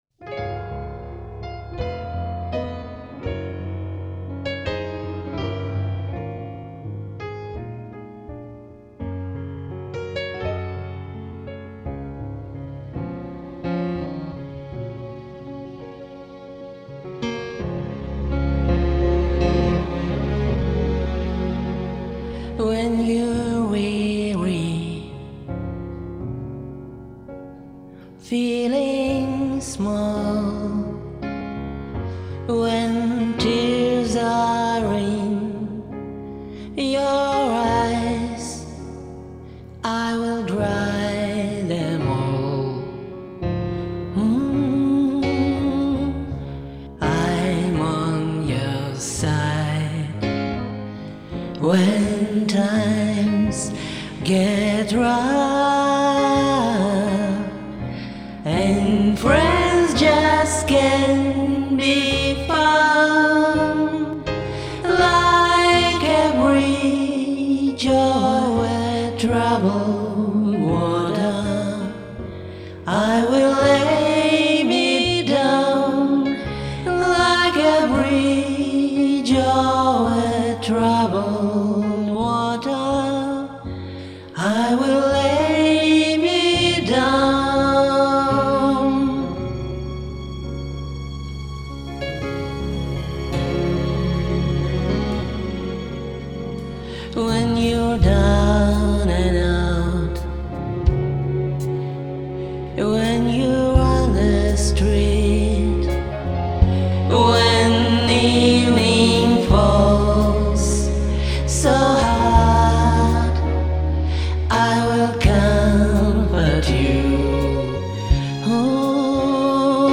Да еще и двухголосие! bravobuket_serdechki